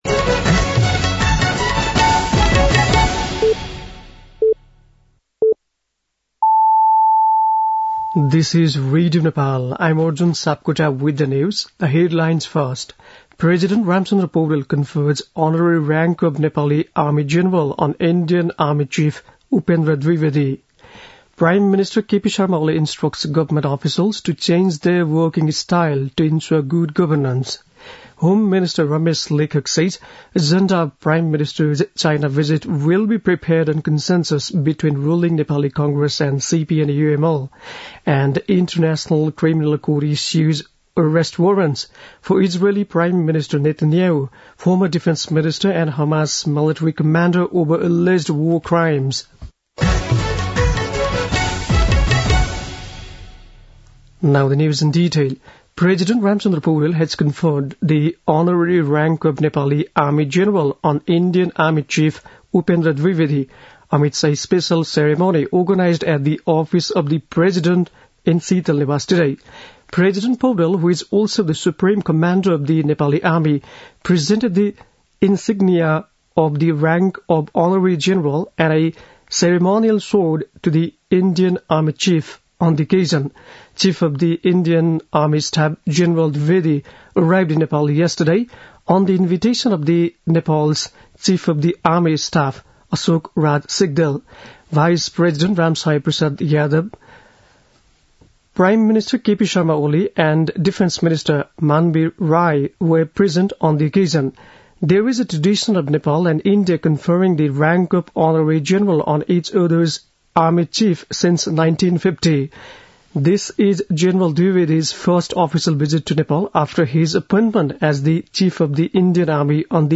बेलुकी ८ बजेको अङ्ग्रेजी समाचार : ७ मंसिर , २०८१
8-pm-english-news-8-6.mp3